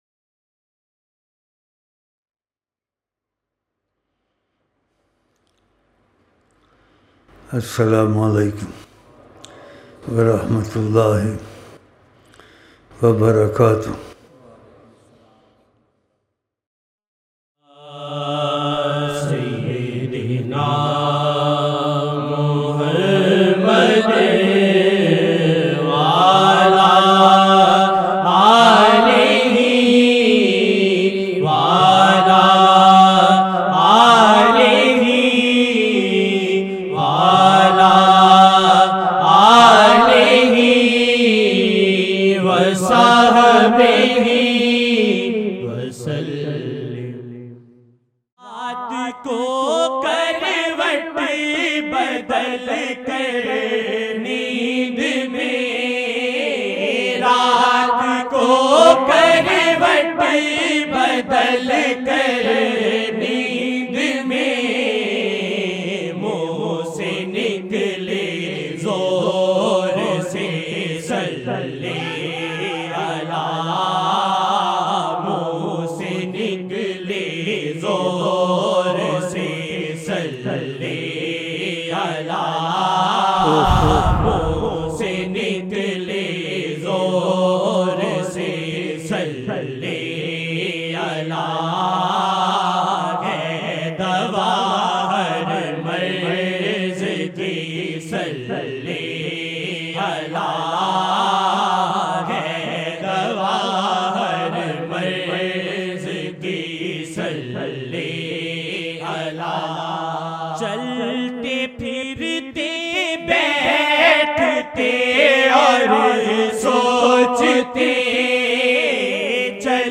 01-March-2013-Esha-Mehfil-18 Rabi-ul-Sani 1434